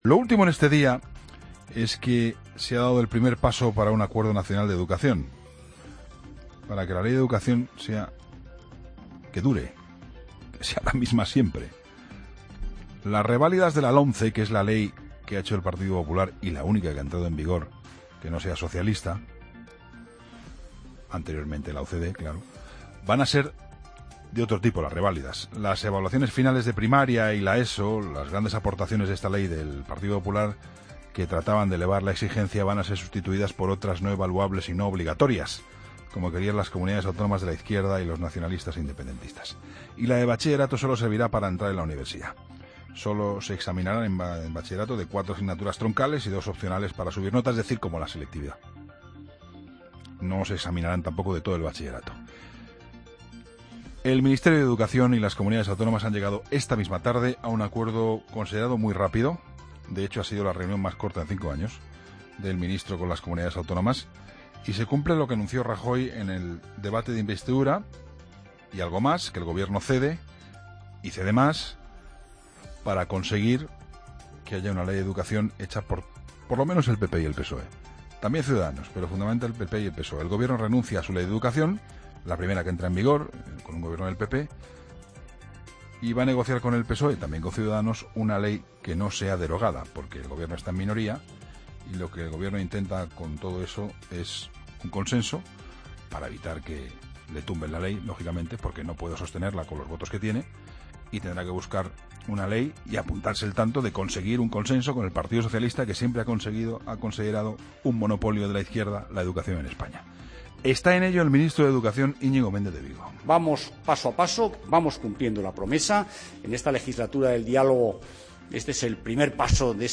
AUDIO: El análisis de Juan Pablo Colmenarejo en 'La Linterna'